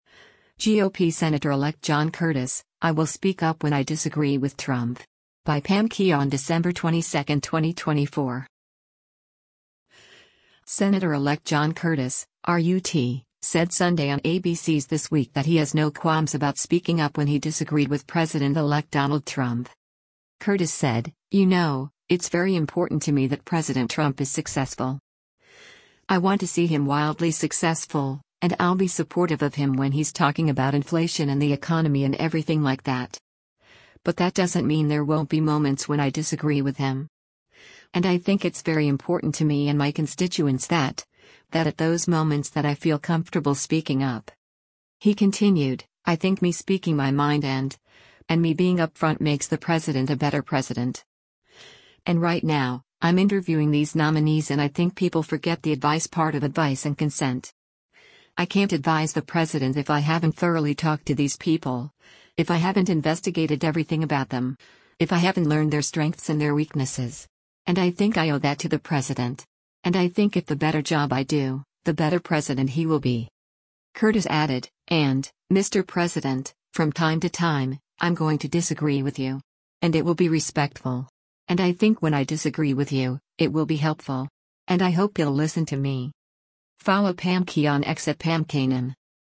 Senator-Elect John Curtis (R-UT) said Sunday on ABC’s “This Week” that he has no qualms about speaking up when he disagreed with President-elect Donald Trump.